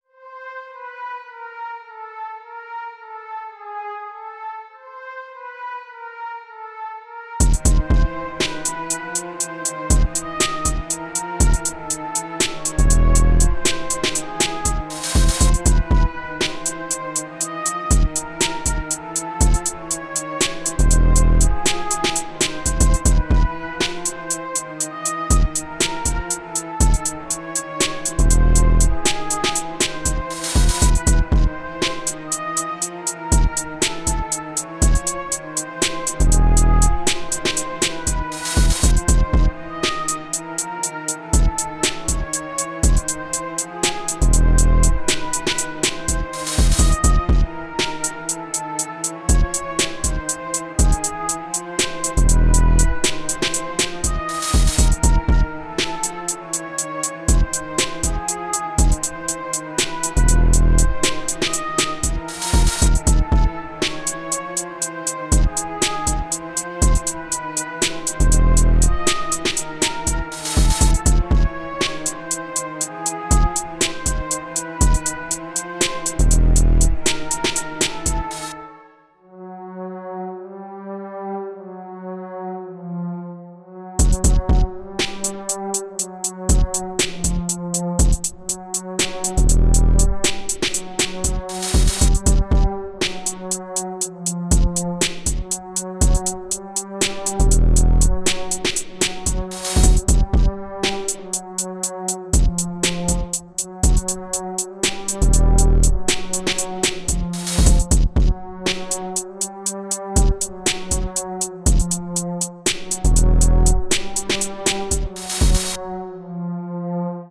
Trap Агрессивный 125 BPM